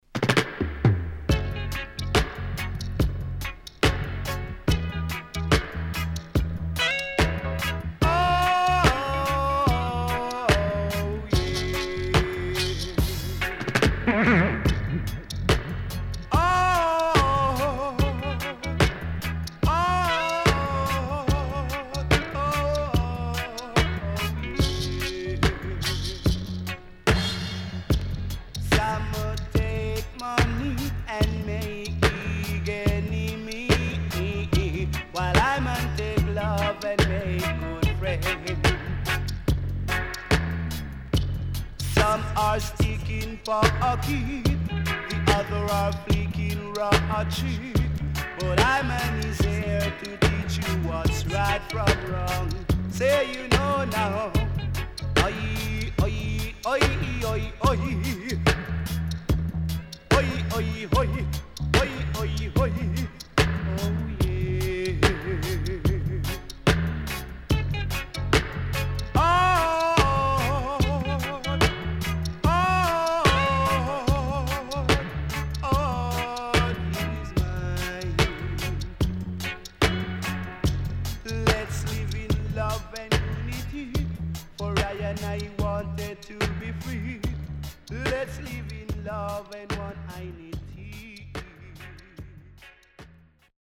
HOME > LP [DANCEHALL]  >  EARLY 80’s
SIDE A:少しノイズ入りますが良好です。
SIDE B:少しノイズ入りますが良好です。